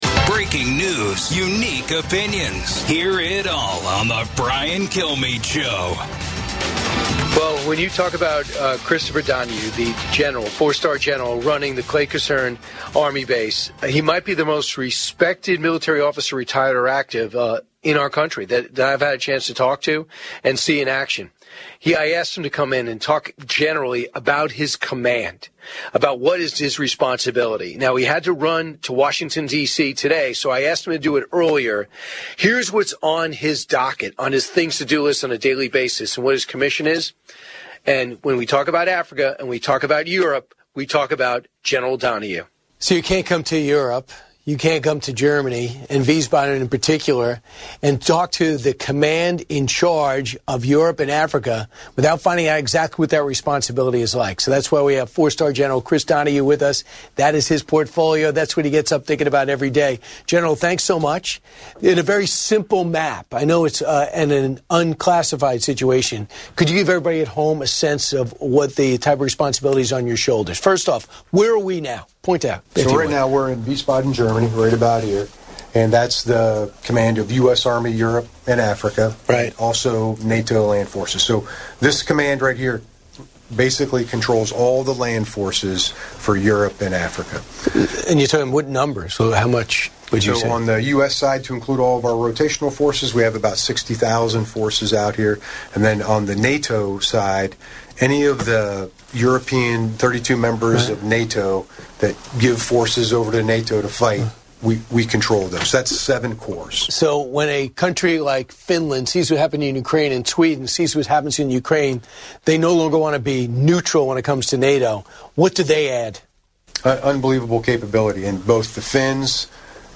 General Chris Donahue, the four-star general in charge of US Army Europe and Africa, discusses his daily responsibilities and the challenges of deterring Russia's expansionist power in Europe and Africa. He highlights the importance of the Eastern Flank deterrence line and the need to eliminate strategic dilemmas in the region, particularly in Ukraine and Africa.